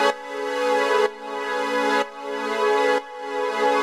Index of /musicradar/sidechained-samples/125bpm
GnS_Pad-alesis1:2_125-A.wav